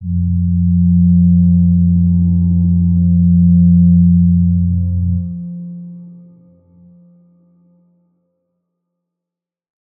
G_Crystal-F3-pp.wav